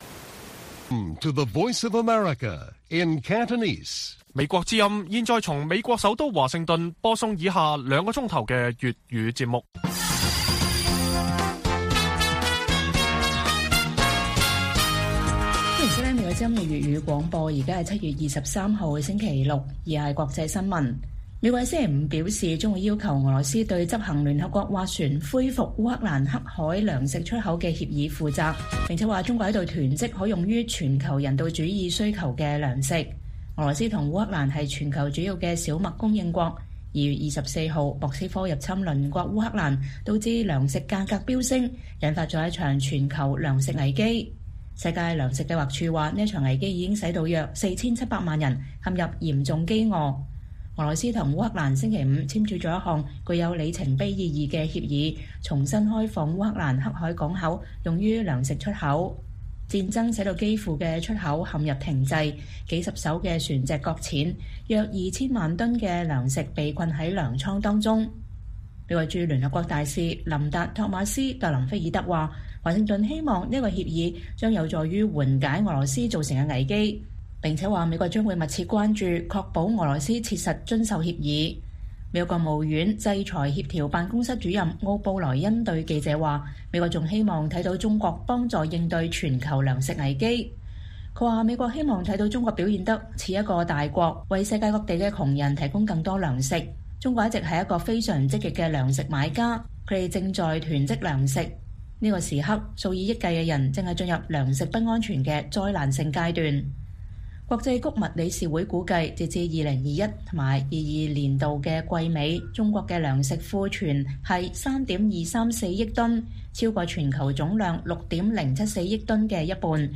粵語新聞 晚上9-10點：美國敦促俄羅斯履行烏克蘭糧食協議，稱中國正在囤積糧食